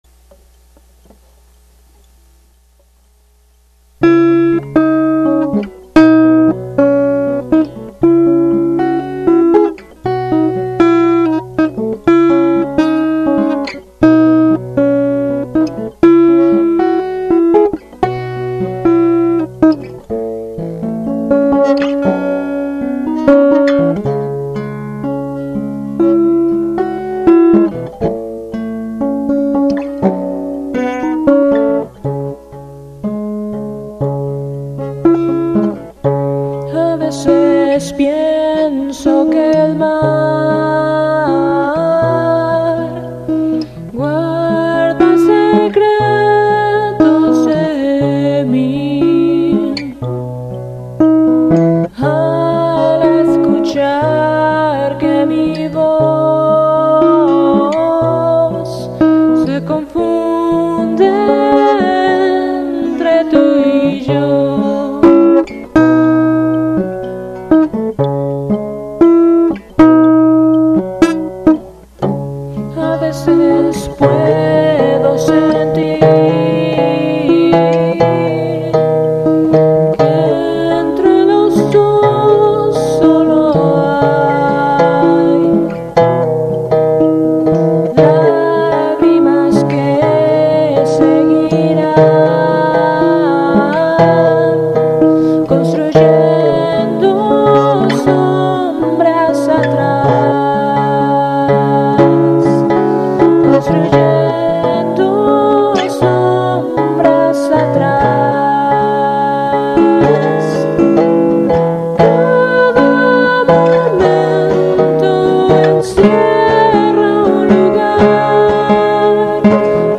Empezamos a acoplar nuestras voces mezzosoprano
y empezamos a ajustar algunas canciones a la simplicidad de una guitarra acústica y un teclado.